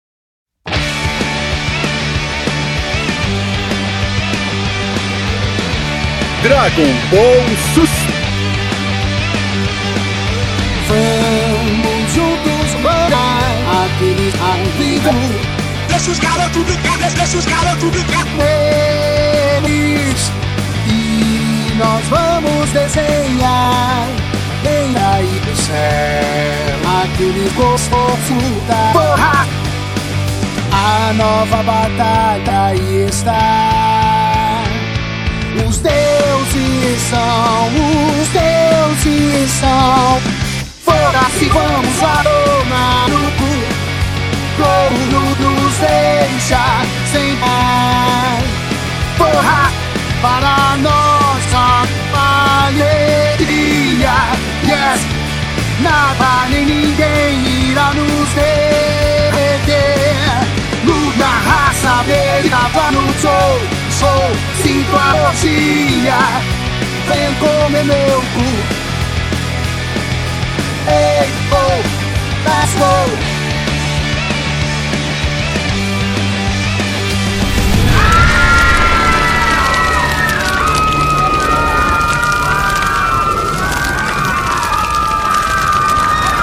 2024-11-01 00:31:54 Gênero: Trap Views